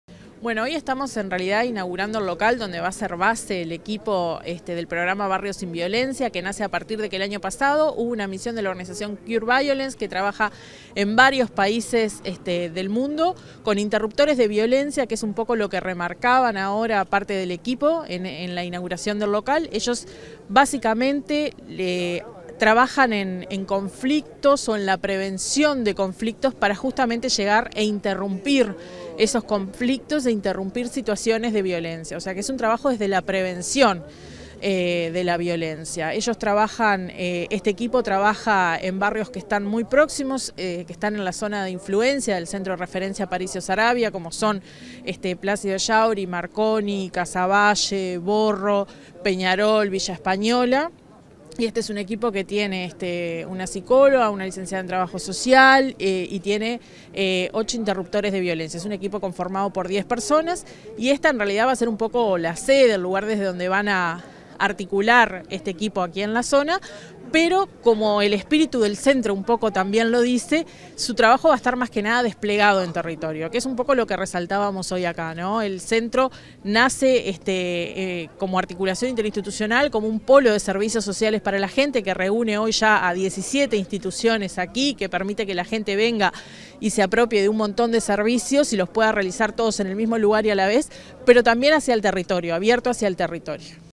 Entrevista a la directora Territorial Departamental del Mides, Carolina Murphy